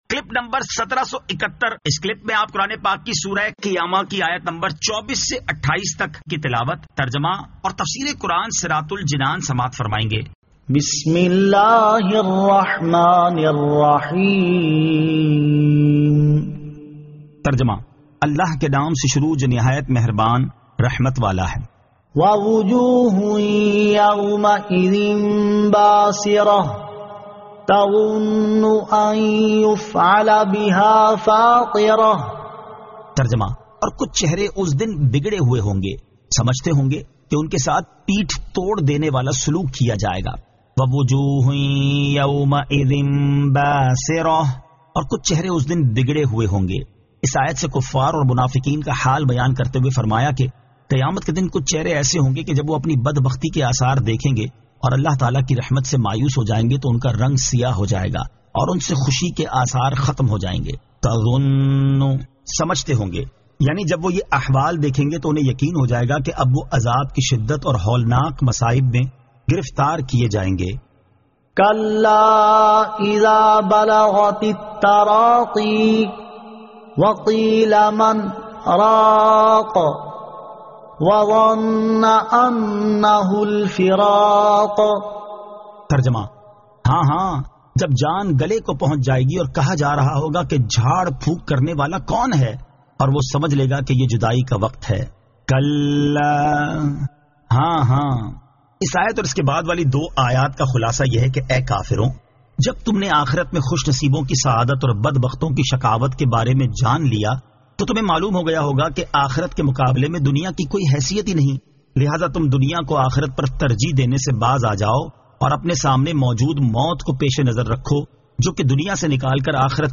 Surah Al-Qiyamah 24 To 28 Tilawat , Tarjama , Tafseer